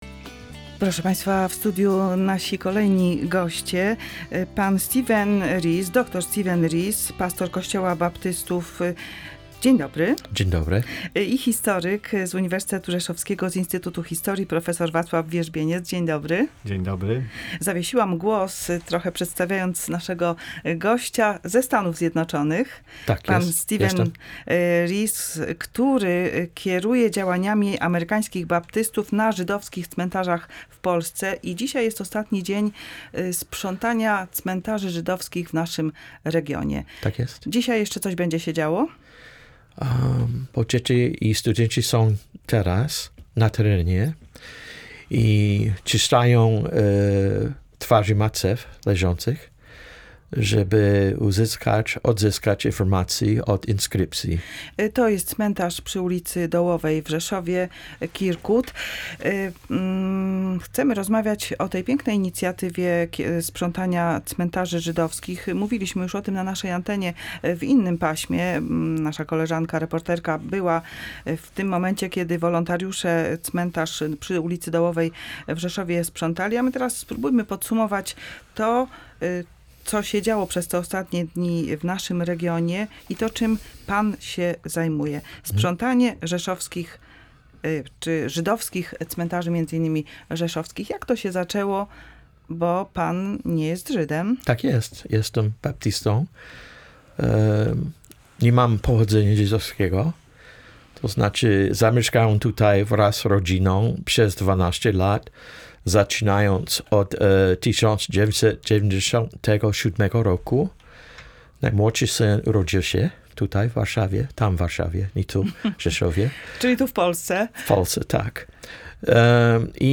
Akcja sprzątania cmentarzy żydowskich • LIVE • Polskie Radio Rzeszów